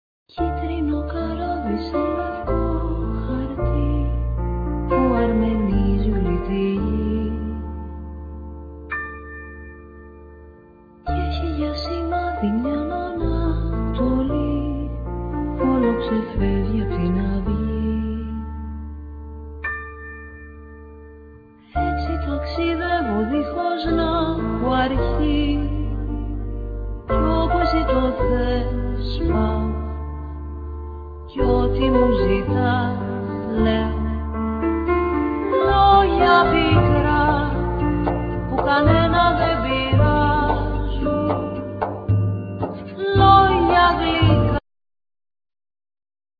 other musicians   vocals
guitar
bass
percussion,samples
synth,drums,udo
flute